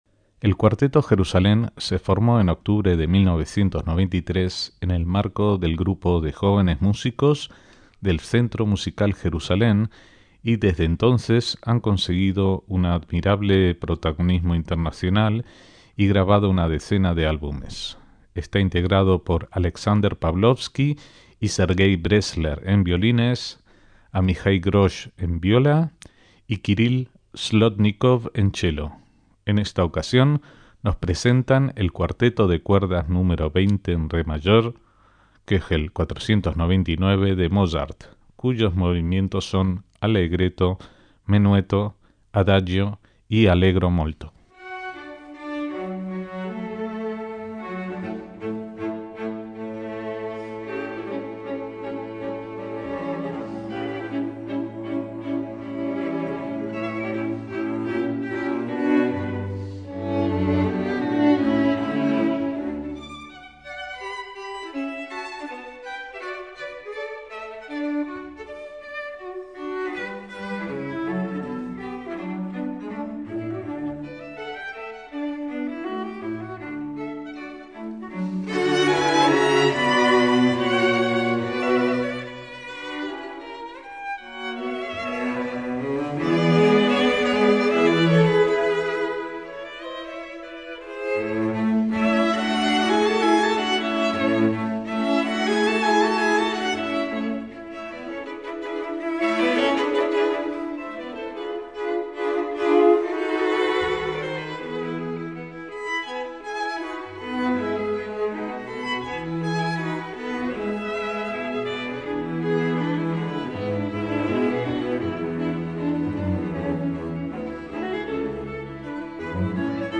primer violín
segundo violín
chelo